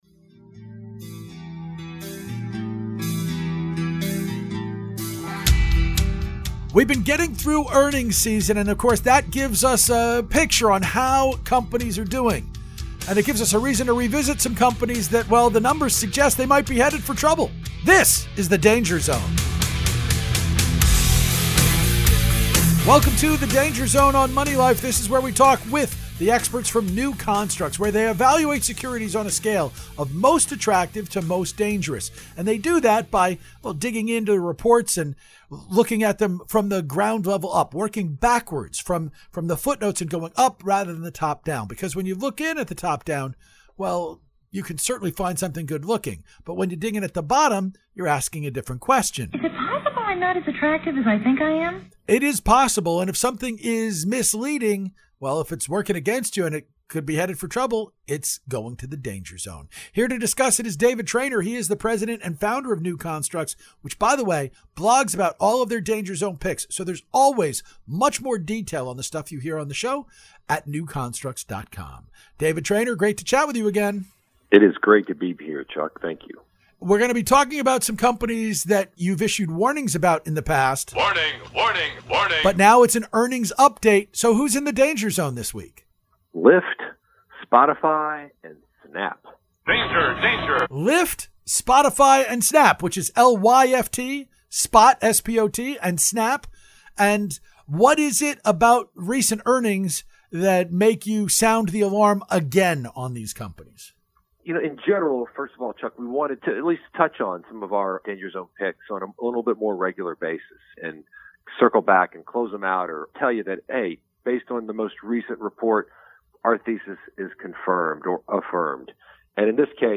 Danger Zone interview